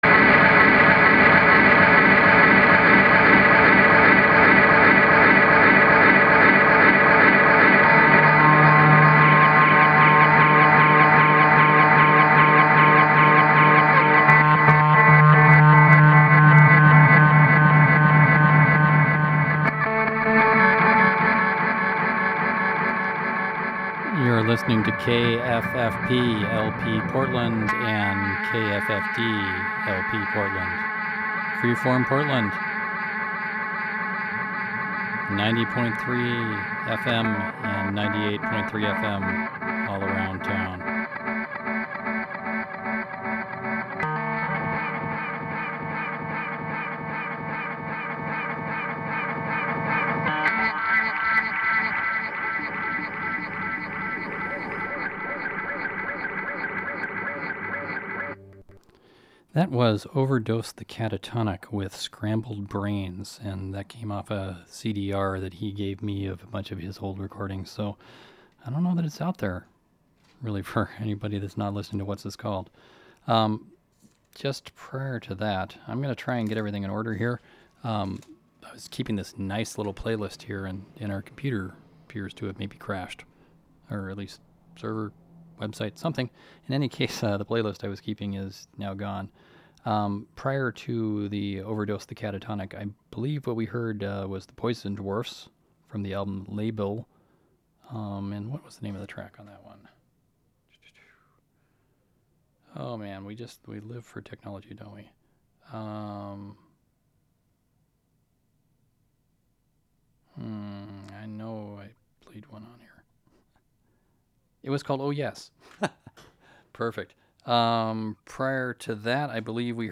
What’s This Called? happened Saturday 2/16 in it’s usual time at Freeform Portland from 2-4 PM Pacific Standard Time. However, we are also substituted for Unclaimed Baggage on Sunday 2/17 4-6 PM PST. 4 hours of strange, noisy, droney, plunderphonic, psychedelic, post-punk, outsider music!